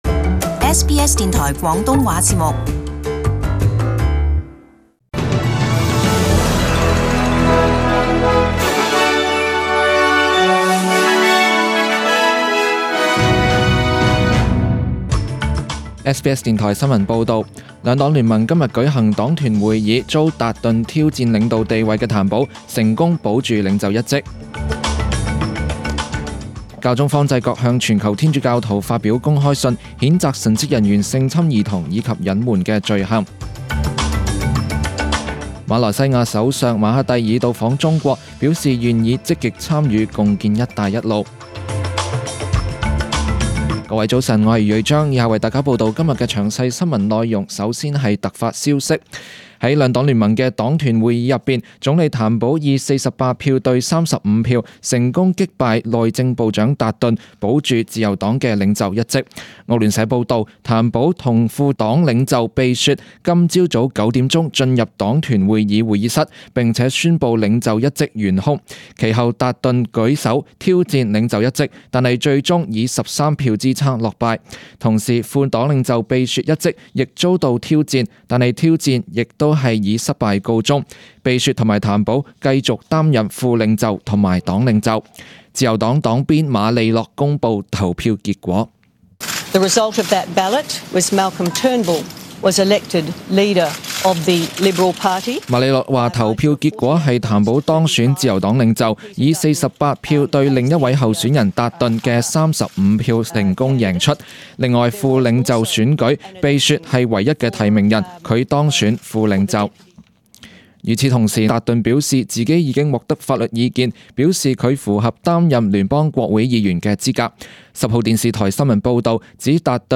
SBS中文新闻 （八月二十一日）
请收听本台为大家准备的详尽早晨新闻。